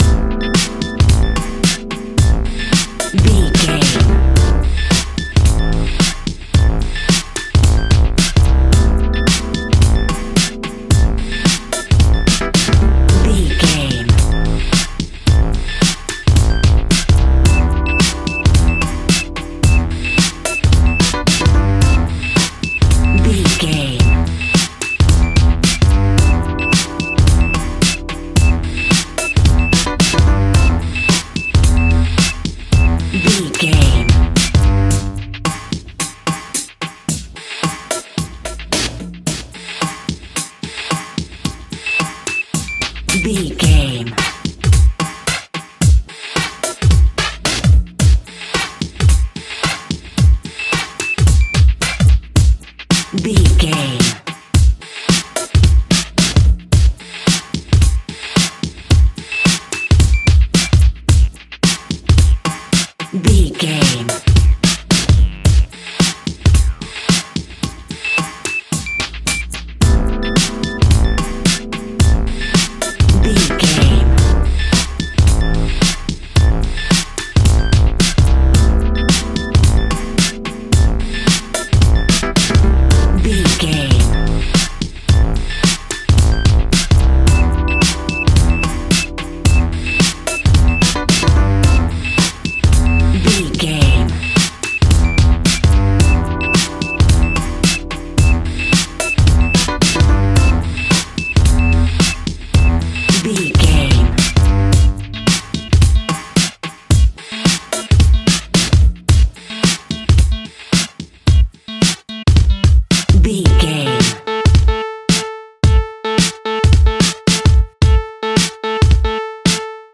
Aeolian/Minor
hip hop instrumentals
funky
groovy
east coast hip hop
electronic drums
synth lead
synth bass